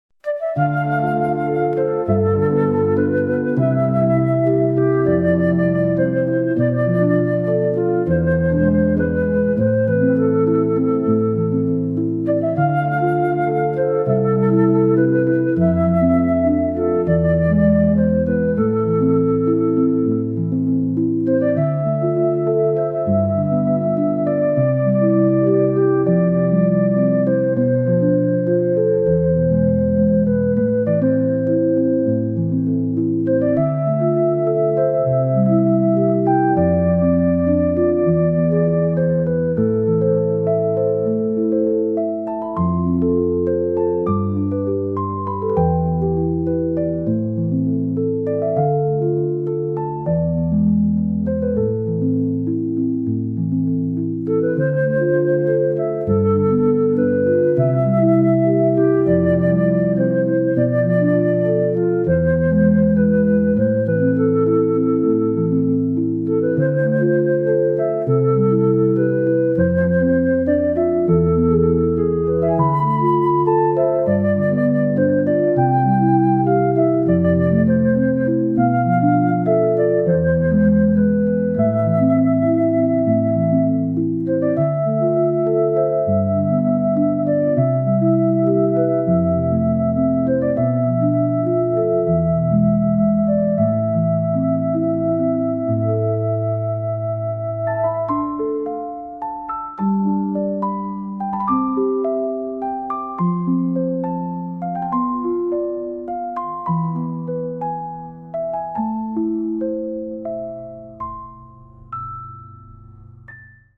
Piano Soundscape
light and peaceful piano piece